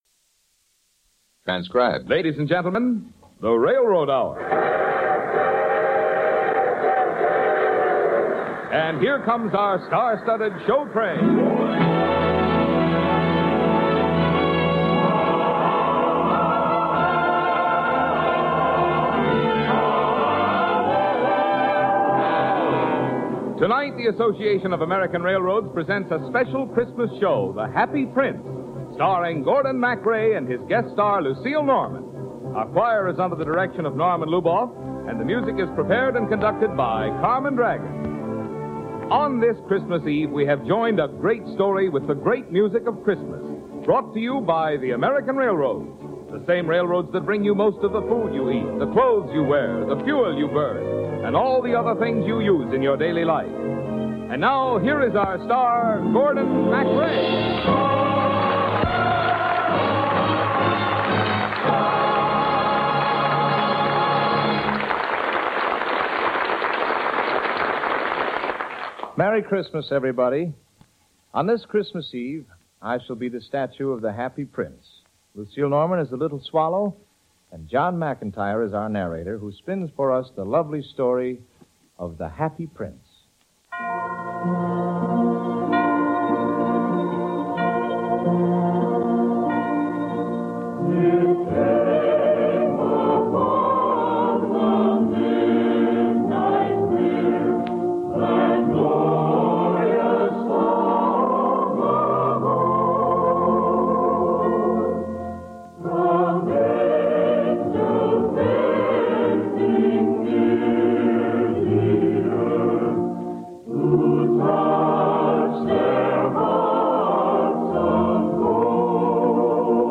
The Railroad Hour was a delightful radio series that aired musical dramas and comedies from the late 1940s to the mid-1950s. Sponsored by the Association of American Railroads, the show condensed beloved musicals and operettas into shorter formats, focusing on works written before 1943.